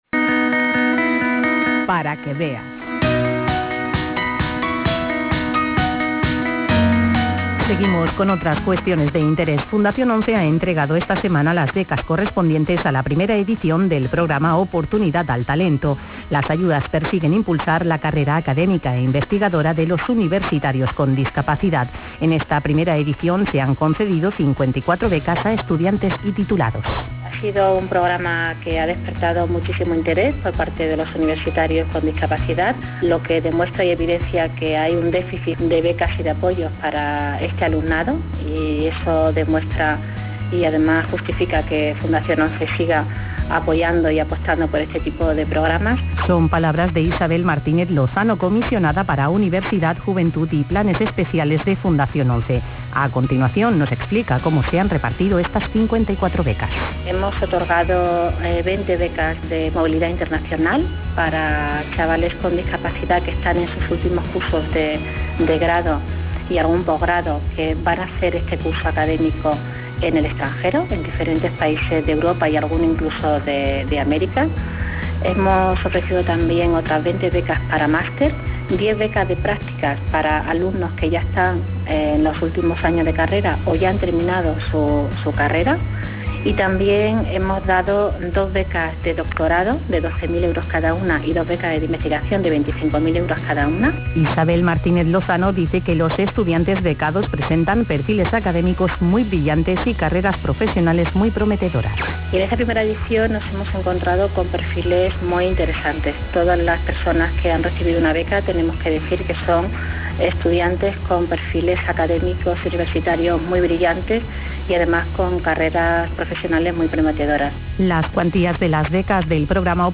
según explicaban varios de sus protagonistas en declaraciones al programa "Para que veas", que realizan ONCE y su Fundación para Radio 5 de Radio Nacional.